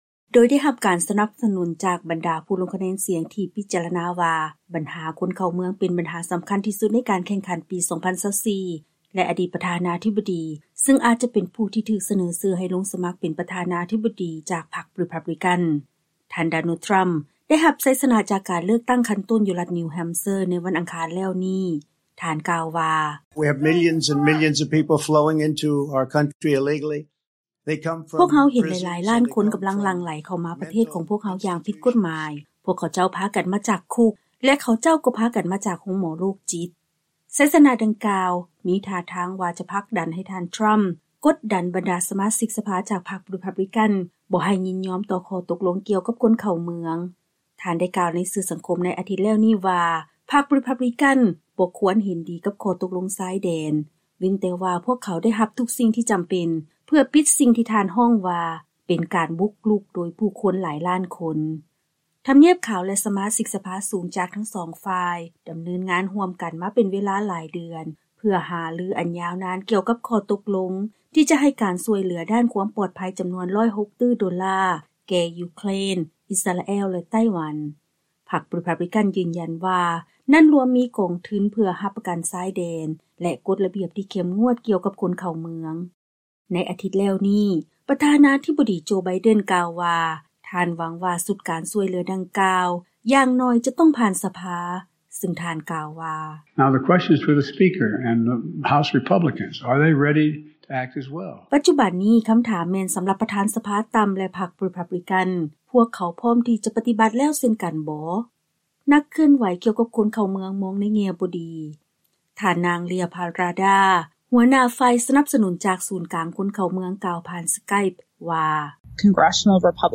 ລາຍງານກ່ຽວກັບ ທ່ານ ທຣໍາ ແລະ ບັນຫາການໃຫ້ທຶນແກ່ ຢູເຄຣນ, ອິສຣາແອລ ແລະ ໄຕ້ຫວັນ.